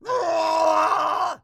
pigman_angry2.wav